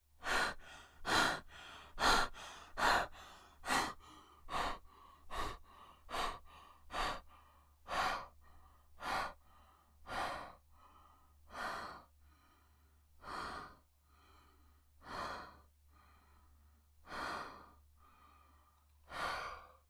breath-male.wav